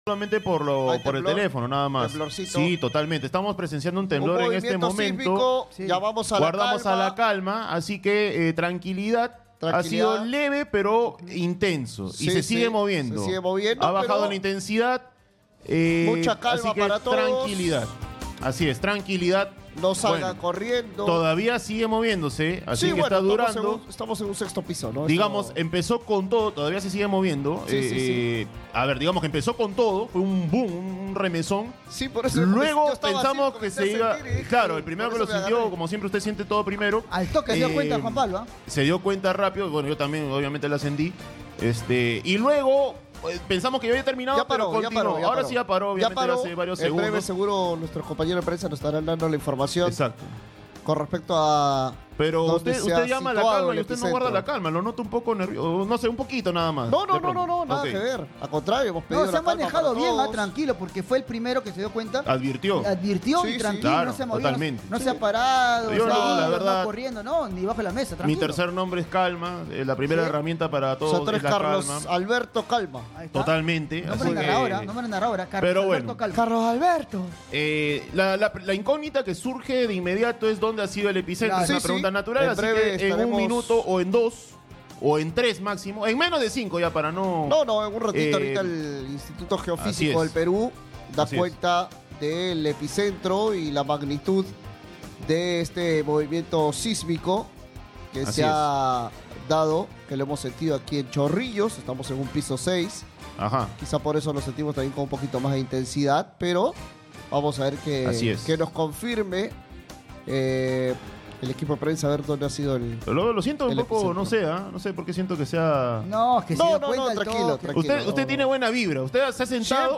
El panel de Exitosa Deportes fue sorprendido por el sismo de magnitud 5.0 que remeció Pisco, Ica, la tarde de este martes, 2 de septiembre.